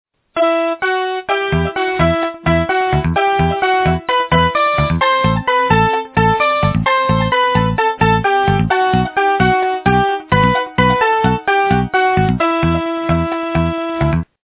русская эстрада